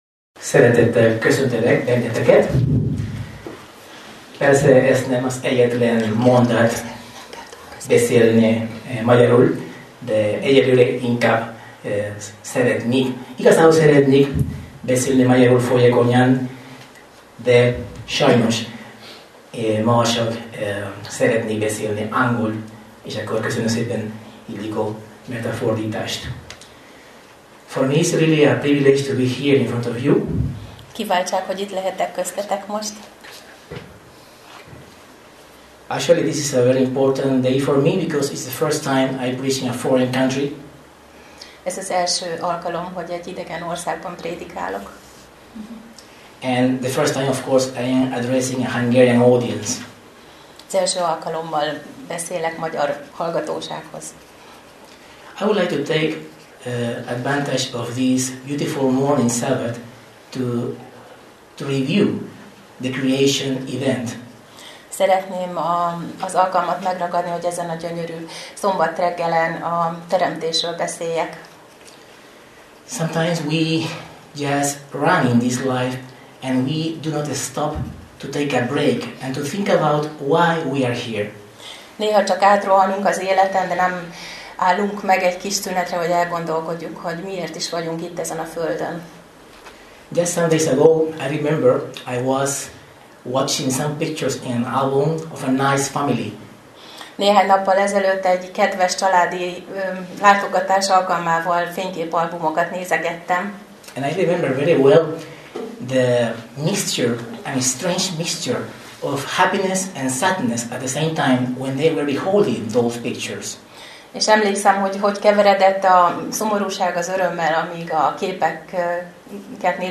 Szombati igehirdetés